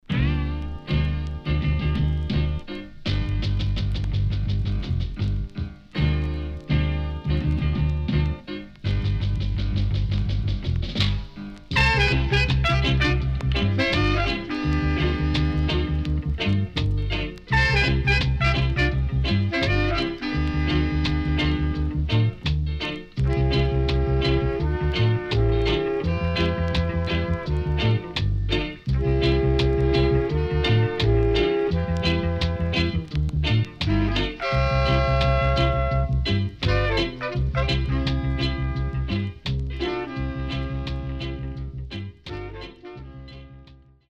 riddim
SIDE A:プレス起因により少しノイズ入りますが良好です。